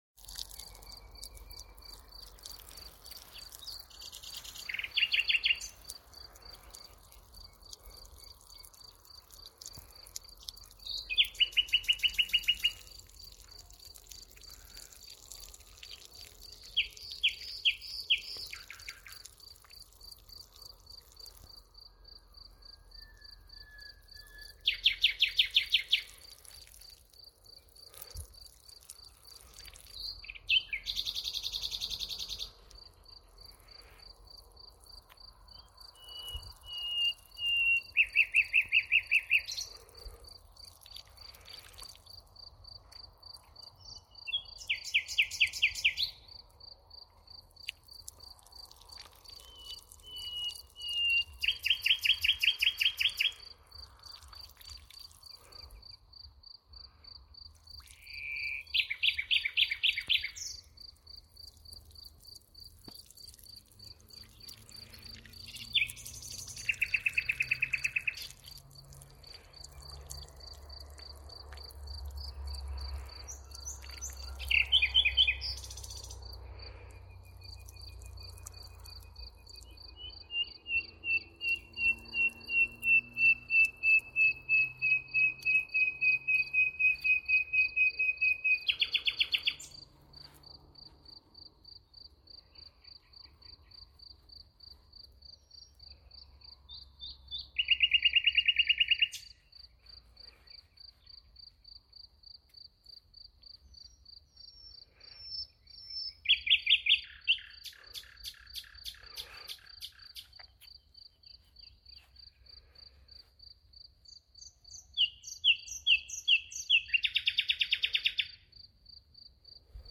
Die Nachtigall war es wohl, die unserem Webmaster bei seiner abendlichen Heimkehr ein wunderbares Liedchen sang, das er so schnell nicht mehr vergessen wird.
Ihr Gesang ist nicht nur einzigartig, sondern ist auch immer wieder fester Bestandteil in Literatur und Schauspiel.
Nachtigall.mp3